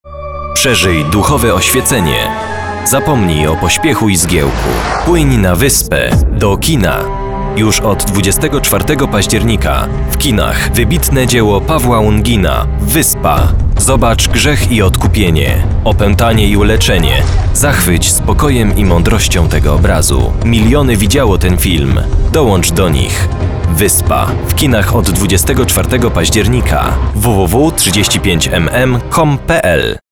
Professioneller polnischer Sprecher für TV / Rundfunk / Industrie.
Sprechprobe: Werbung (Muttersprache):
polish voice over artist